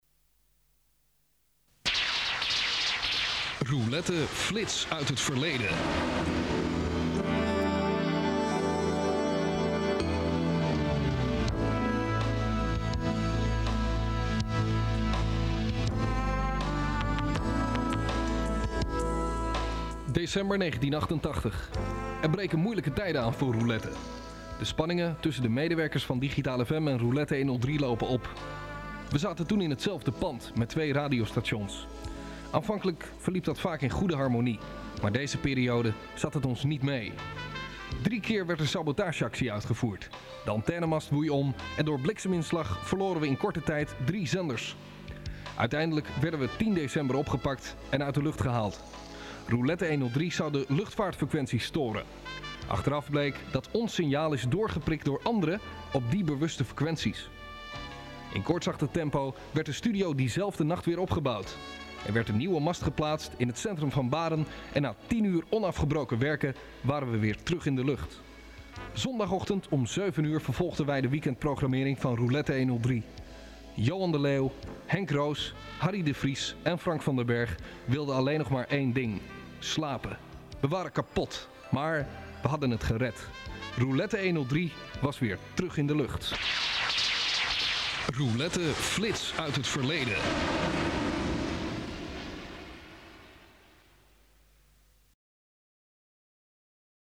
Alles op cassette en spoelen (bandrecorders)